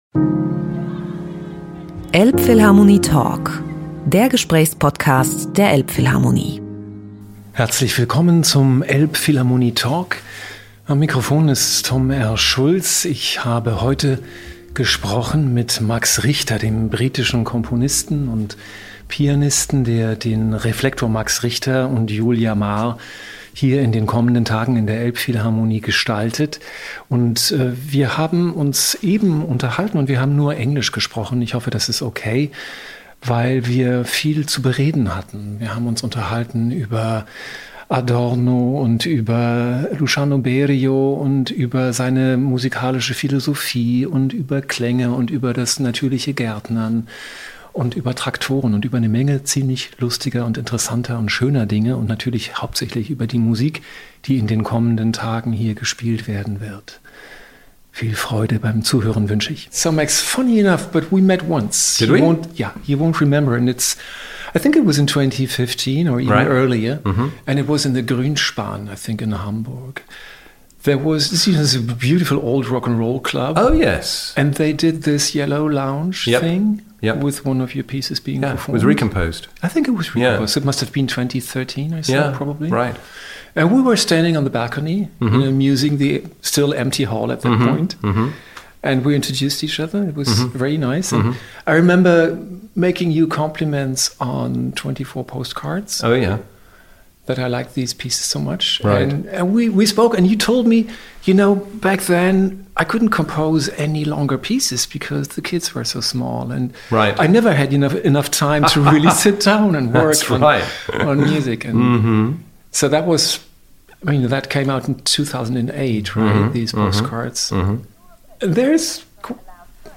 elbphilharmonie-talk-mit-max-richter-mmp.mp3